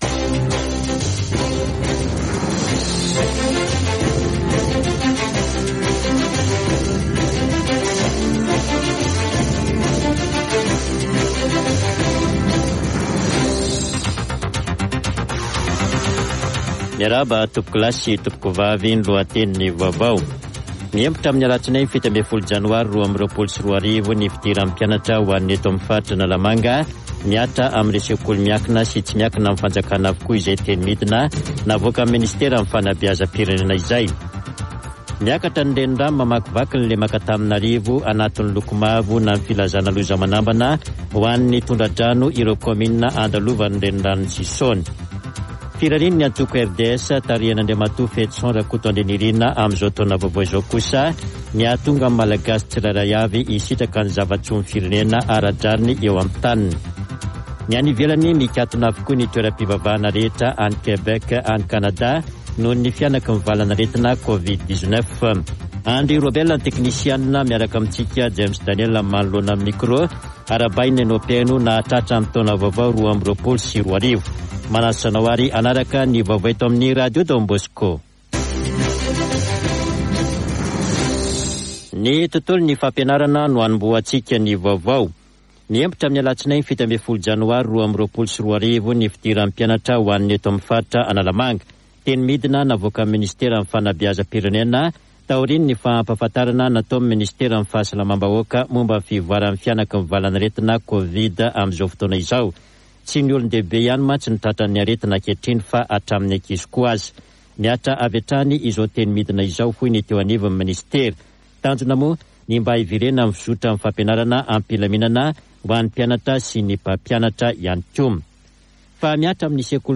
[Vaovao hariva] Talata 4 janoary 2022